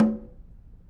Snare2-HitNS_v1_rr1_Sum.wav